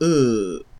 ɤ-Close-mid_back_unrounded_vowel.ogg.mp3